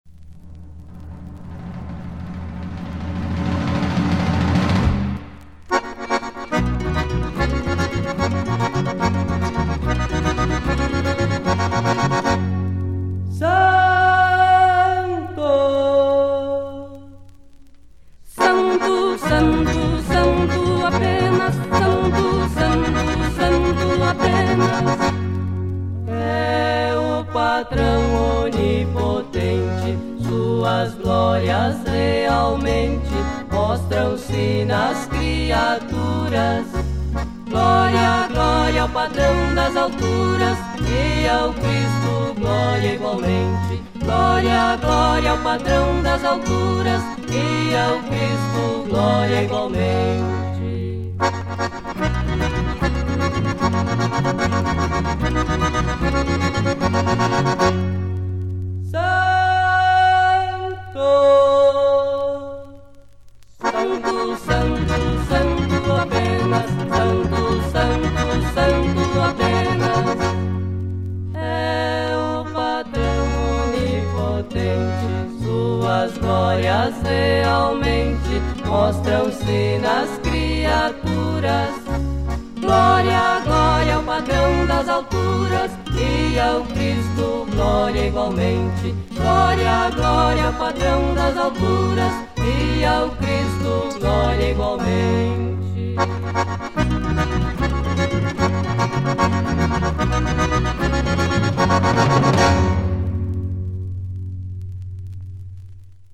Santo (Fantasia)